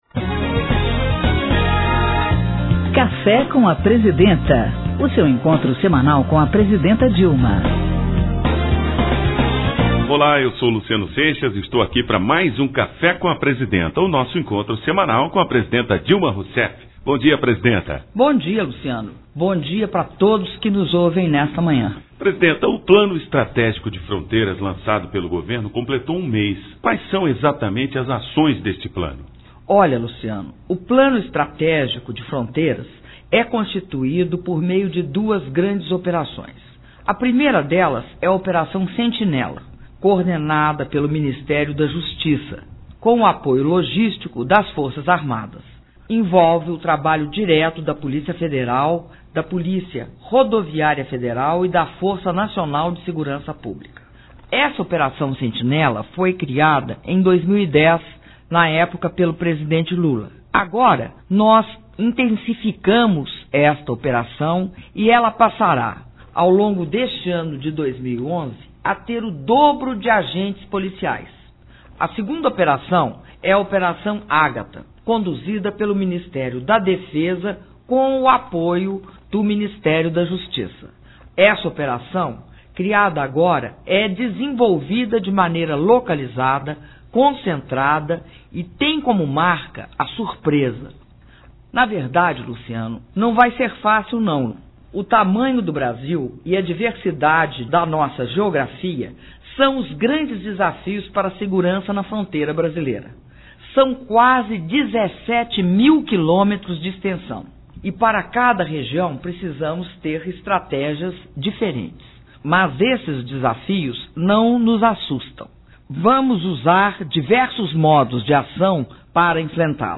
Programa de rádio “Café com a Presidenta”, com a Presidenta da República, Dilma Rousseff